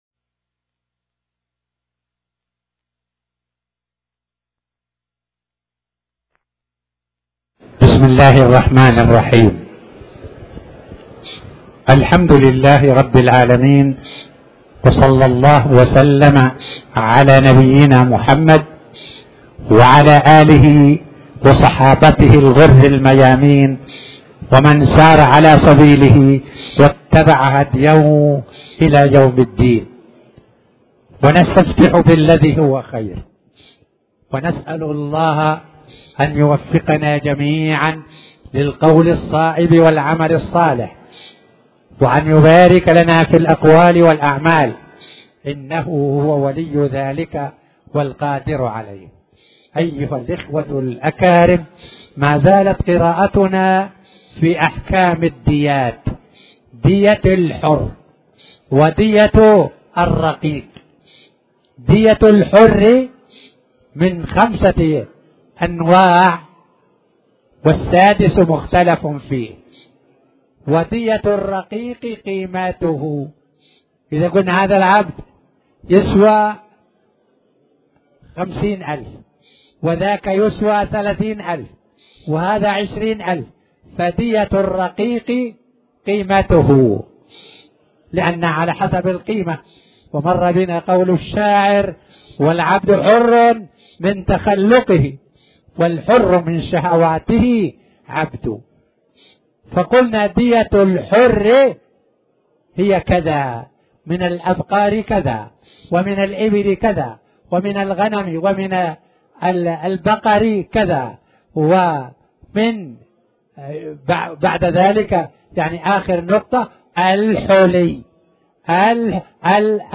تاريخ النشر ٩ ذو القعدة ١٤٣٩ هـ المكان: المسجد الحرام الشيخ